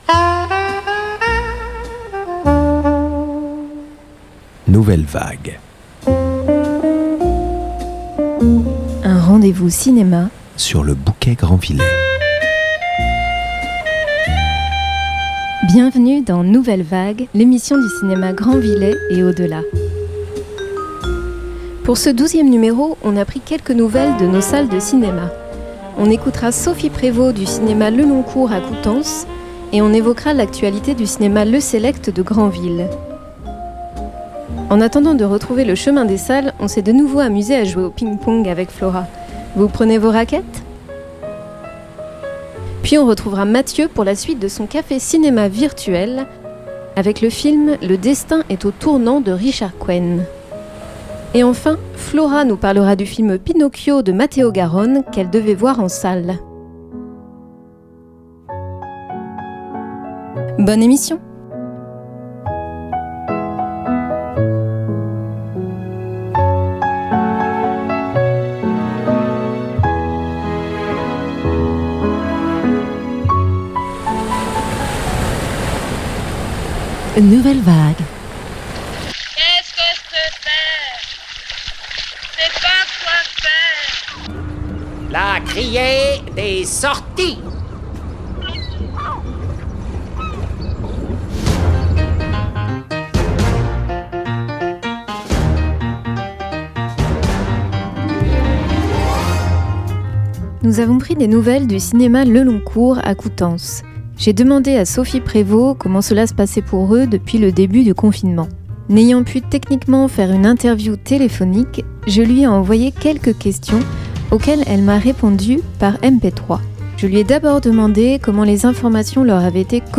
Les extraits de musiques de films qui ponctuent l’émission
Animatrices radio